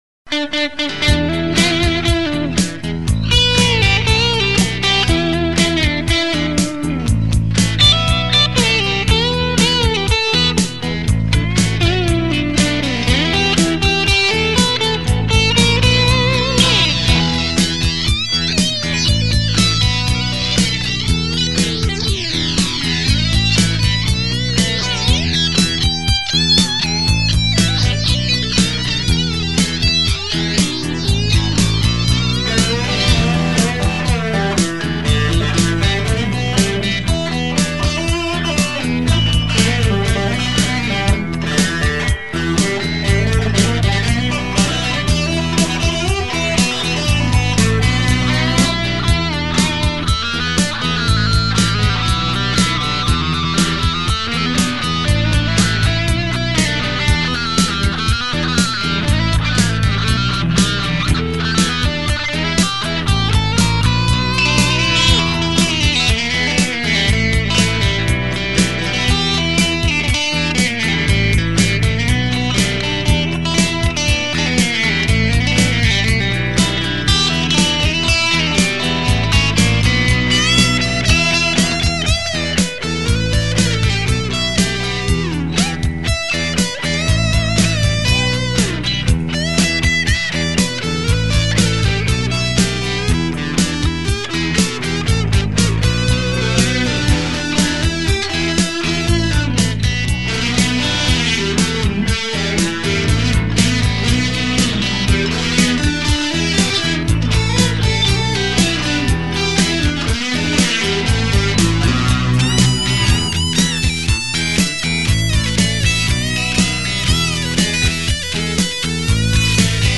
They all get the same meandering, mindless, Tufnelish solos and must exist on their own distorted merits alone.